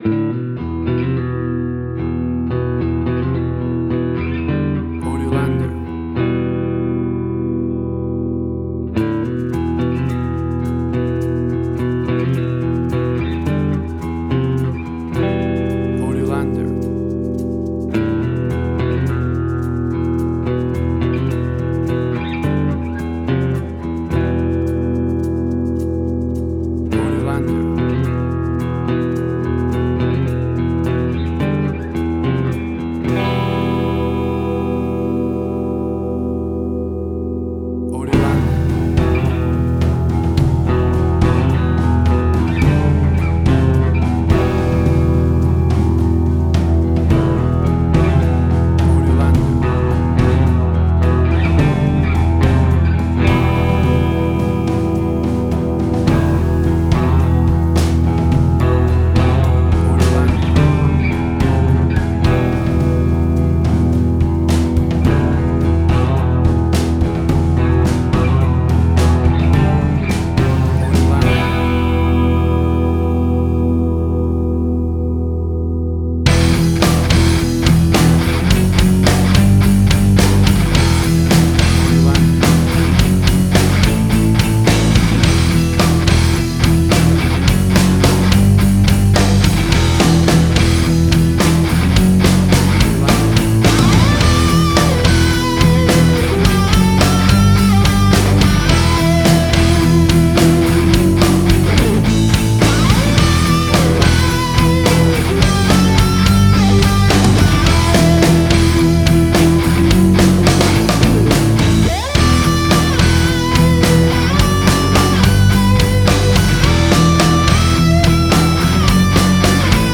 Hard Rock, Similar Black Sabbath, AC-DC, Heavy Metal.
Tempo (BPM): 108